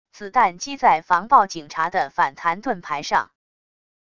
子弹击在防暴警察的反弹盾牌上wav音频